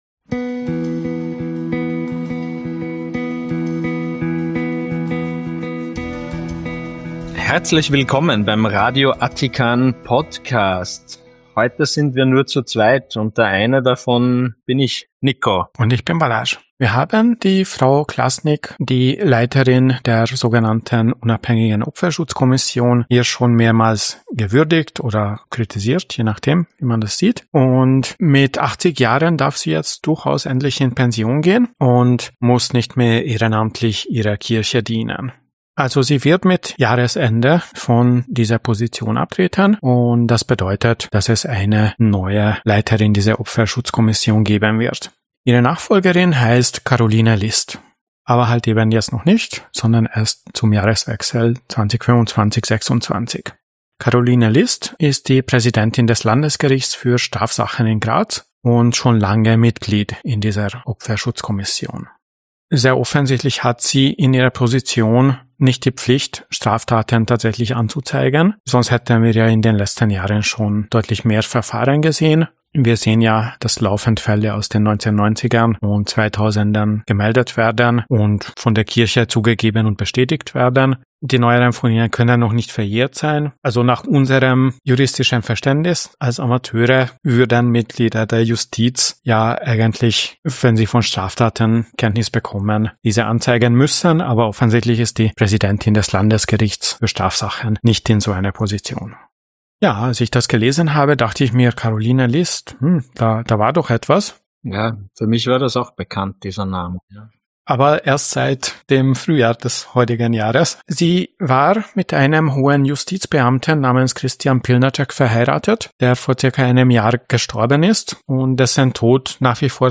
Der kritische Podcast aus Österreich, mit Nachrichten, Themen und Interviews aus der säkularen und skeptischen Szene